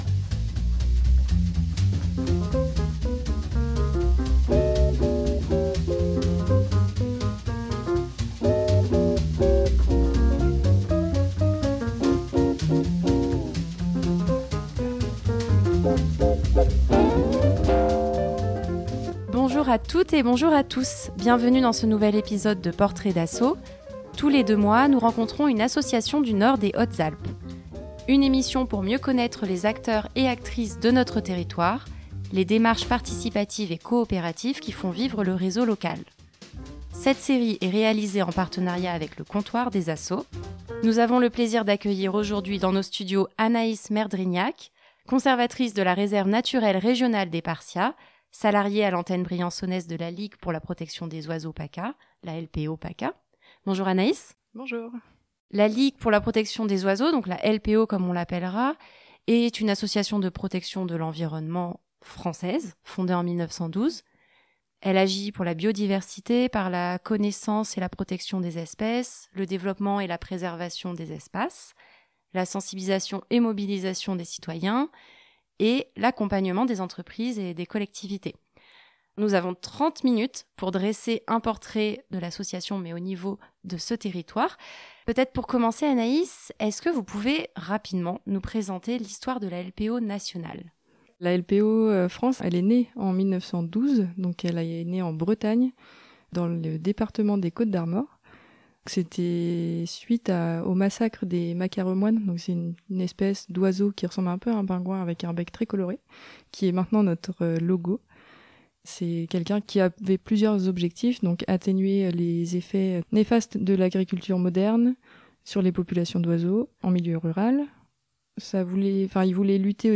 Tous les deux mois, dans Portraits d'assos, nous rencontrons une association du nord des Hautes Alpes.